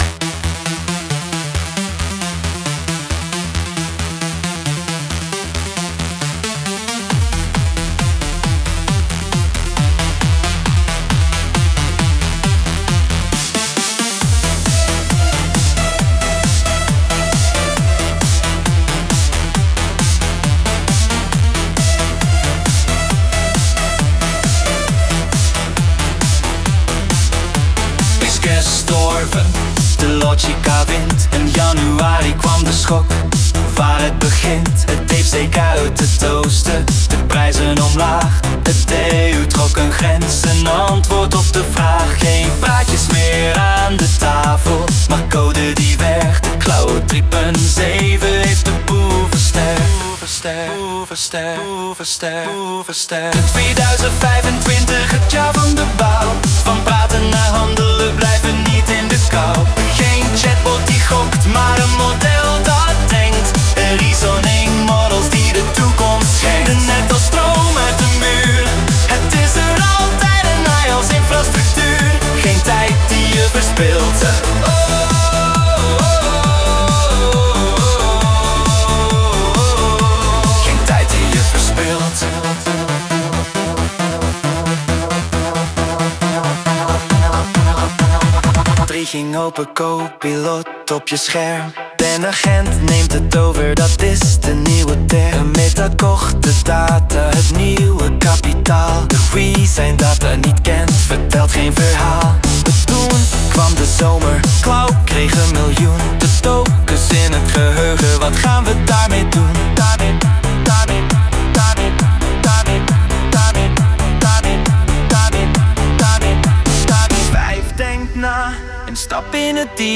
Zo klinkt dit jaaroverzicht als je Gemini 3 Pro en SUNO aan het werk zet.